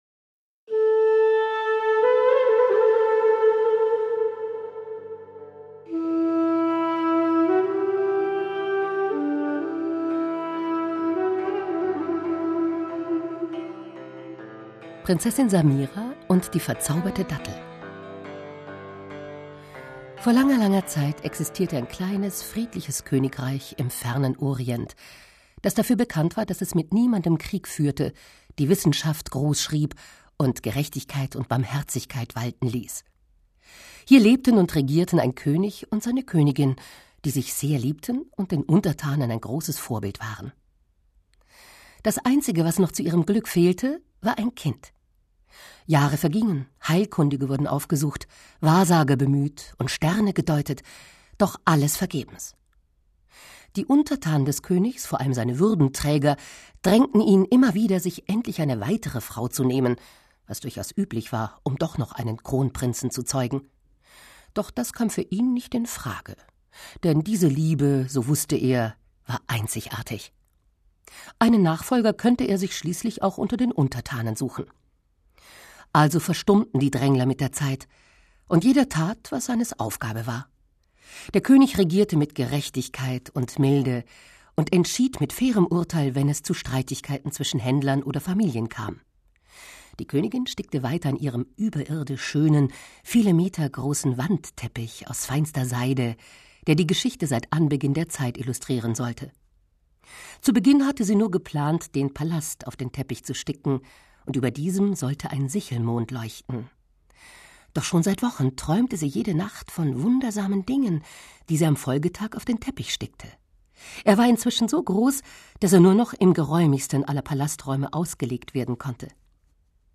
Schlagworte Hörbuch; Lesung für Kinder/Jugendliche • Hörbuch; Märchen/Sagen